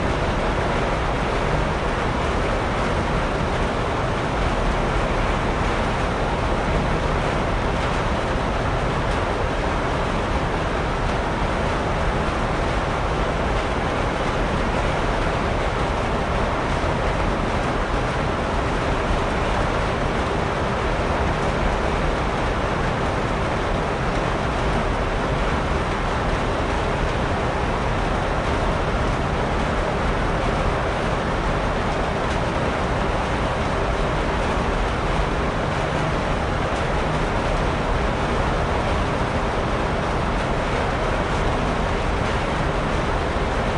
随机的" 马达工业涡轮机大风扇叶片旋转排序
描述：电机工业涡轮大风扇叶片旋转sorta.flac
Tag: 叶片 旋转 涡轮机 马达 风扇 大的工业